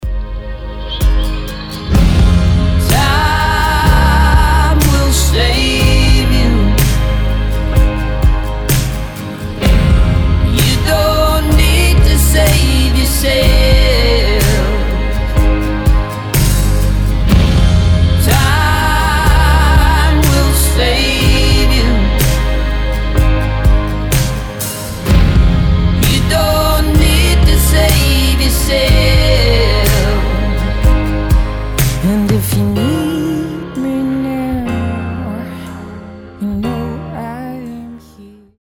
• Качество: 320, Stereo
мужской вокал
романтичные
indie rock
легкий рок